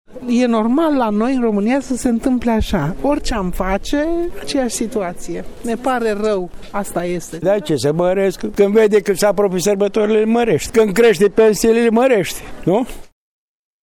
Oamenii spun că s-au obișnuit deja cu scumpirile de Paște:
vox-oua-1.mp3